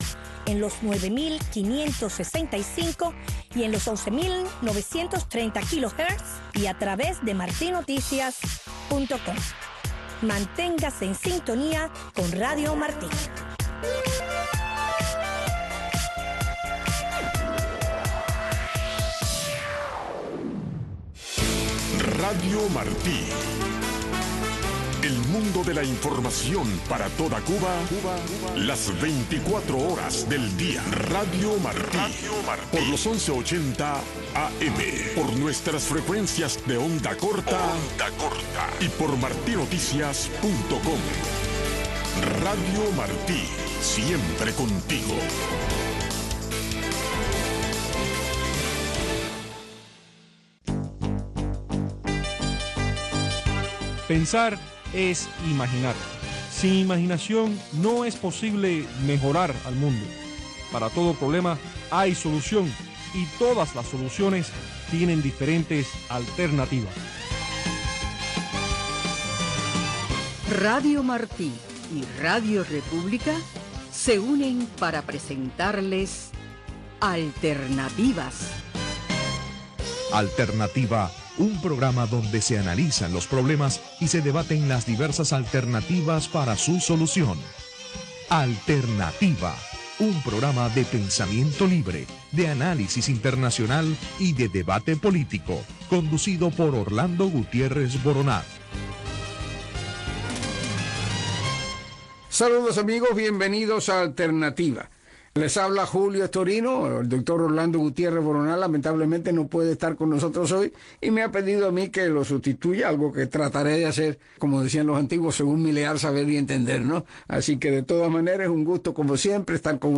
El programa radial Alternativa es un programa de panel de expertos